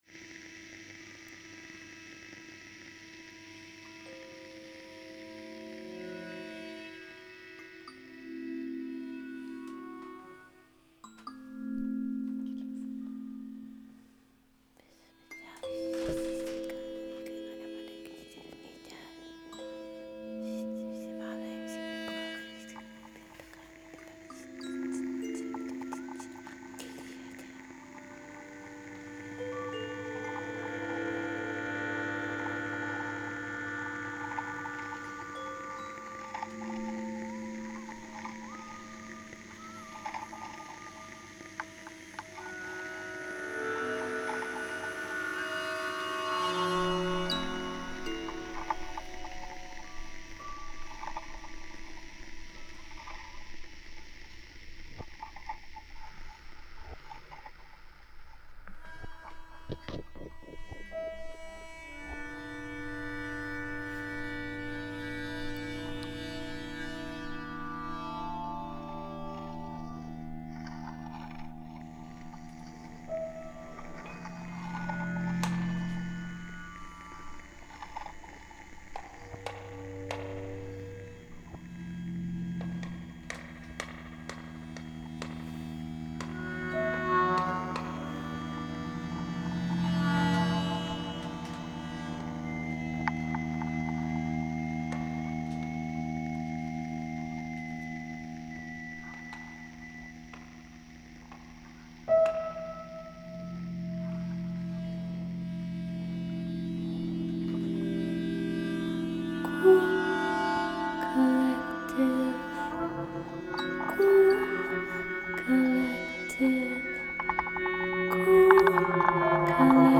Worker-artists convene for a hive broadcast of indiscriminately gathered sound-pollen, transmitting codified messages to kindred folk. Each week a potent gang of sound artists are at play, exploring whatever is on their minds. Style guide: Anything goes.